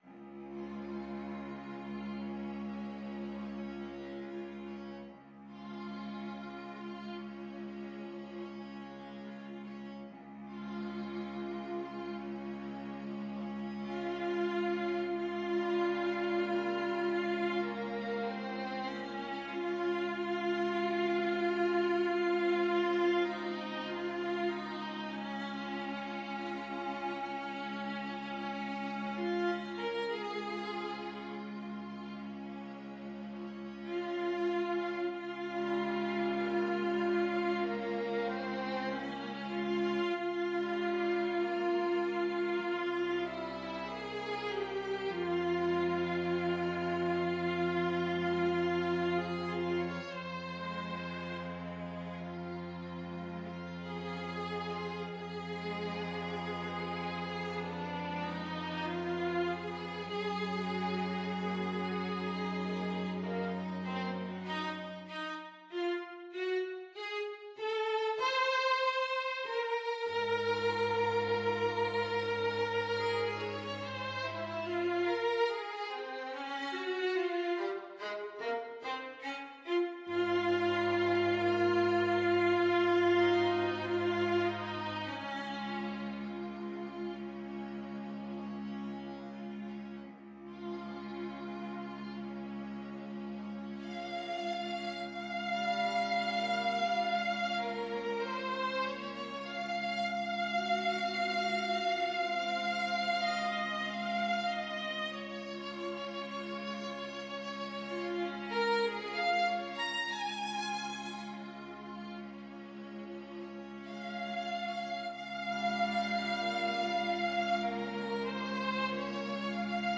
Free Sheet music for String Quartet
Violin 1Violin 2ViolaCello
4/4 (View more 4/4 Music)
Lento placido placido = 48
Db major (Sounding Pitch) (View more Db major Music for String Quartet )
Classical (View more Classical String Quartet Music)